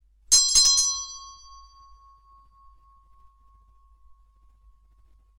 Bell 02
bell brass bronze ding sound effect free sound royalty free Sound Effects